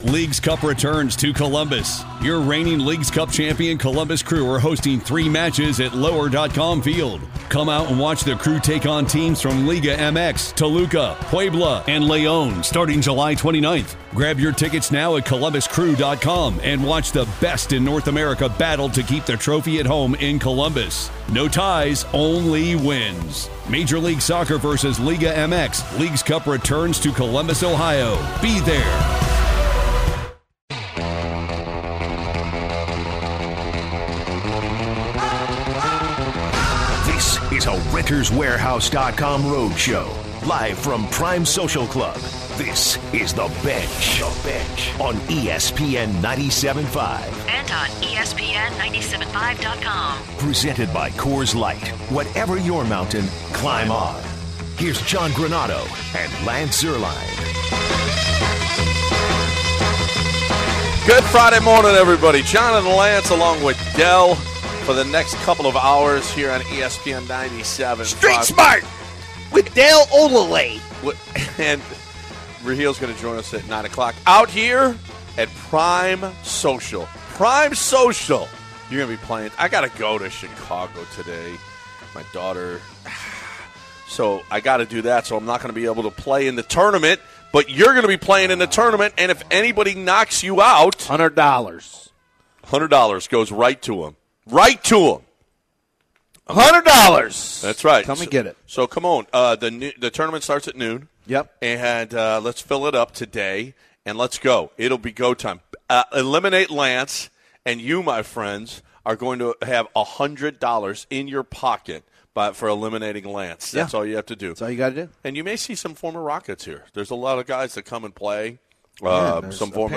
The show kicks off from Prime Social Club.